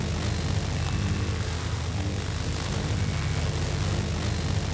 Weapon 05 Loop (Laser).wav